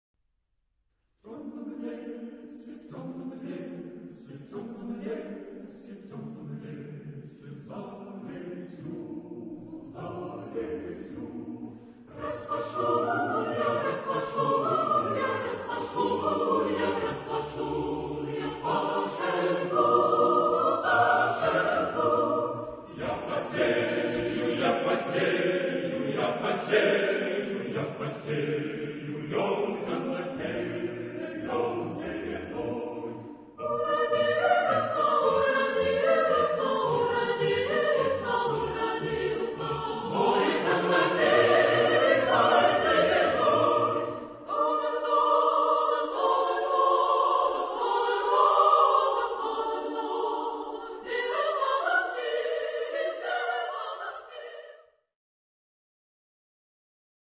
SSAATTBB (7 gemischter Chor Stimmen) ; Chorpartitur mit unterlegtem Klavierauszug für Probezwecke.
Volkslied.
Genre-Stil-Form: Volkslied Charakter des Stückes: unbeschwert ; glücklich Chorgattung: SSAATTBB (7 gemischter Chor Stimmen ) Schwierigkeit Sänger (steigt 1 bis 5) : 2 Schwierigkeit Chorleiter (steigt A bis E) : B Tonart(en): B-dur Dauer: 2.5 Min.